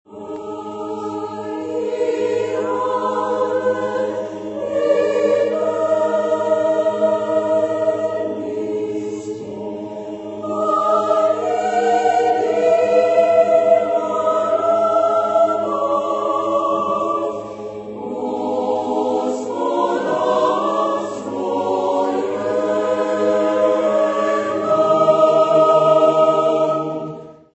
Genre-Style-Forme : Choral ; Neo-Byzantin ; Sacré
Caractère de la pièce : majestueux
Type de choeur : SSAATTBB  (8 voix mixtes )
Solistes : Tenor (1) ad libitum  (1 soliste(s))
Tonalité : Ré dièse Mode de Ré ; Sol diese Mode de La